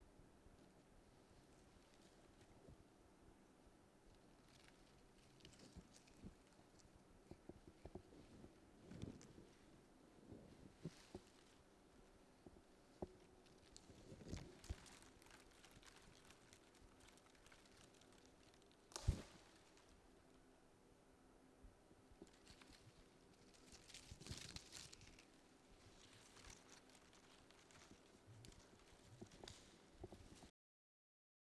rustling-leaves.wav